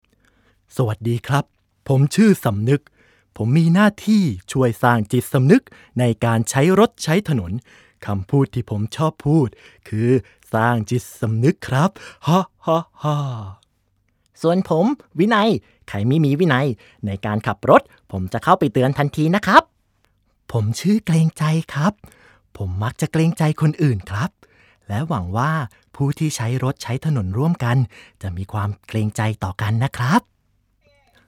角色扮演